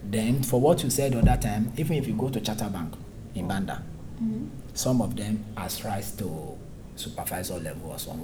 S1 = Bruneian female S3 = Nigerian male
S3 : some of them has rise to (.) supervisor level also Intended Words: has rise , supervisor Heard as: are try , supervise a Discussion: The absence of [h] at the start of has seems to be the biggest problem. We might also note that there is a central or back open vowel in has instead of the [æ] or [ə] that might be expected in the perfective auxiliary has .